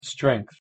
Na końcu strength wymawiasz długie n i bezdźwięczną głoskę th, czyli wysuwasz język między zęby i wypuszczasz powietrze.
pronunciation_en_strength.mp3